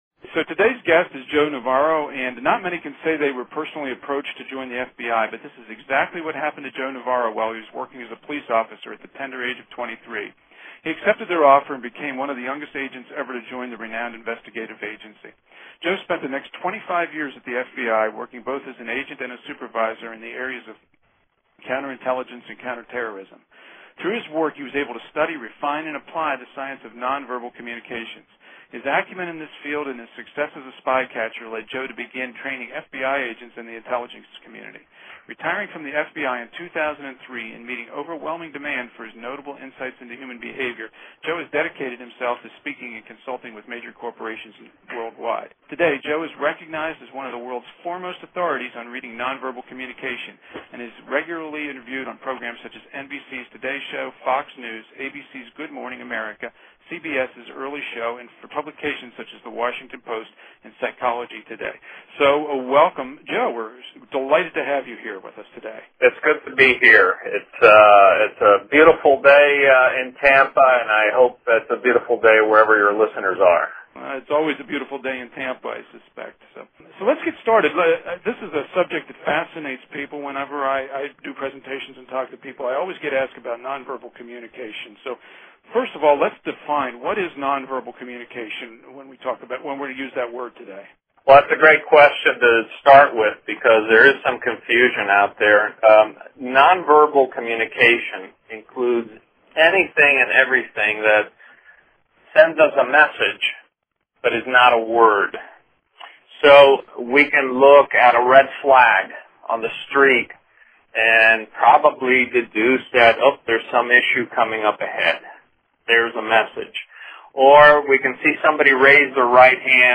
Effective Communication Lesson #10 Nonverbal Communication Here's the interview with Joe!
Joe Navarro Interview complete.mp3